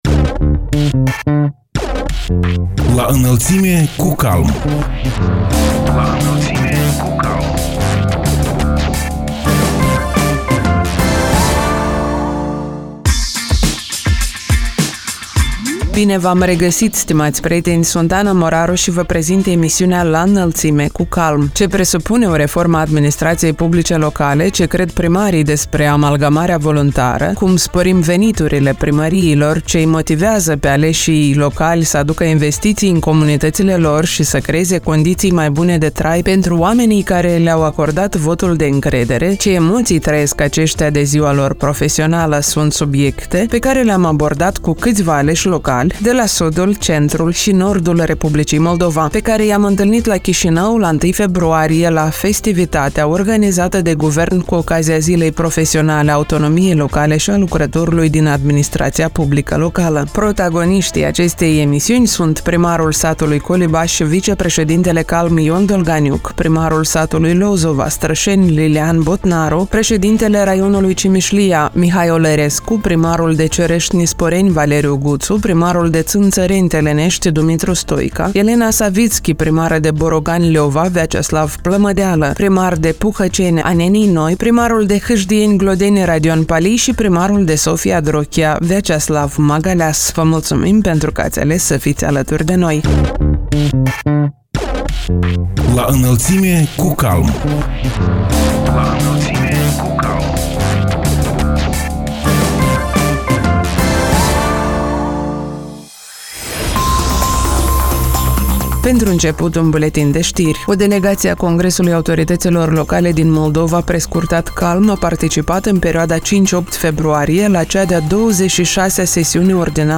Sunt subiecte pe care le-am abordat cu câțiva aleși locali de la sudul, centrul și nordul Republicii Moldova, pe care i-am întâlnit la Chișinău la 1 februarie, la festivitatea organizată de Guvern cu ocazia Zilei Profesionale a Autonomiei Locale și a Lucrătorului din Administrația Publică Locală.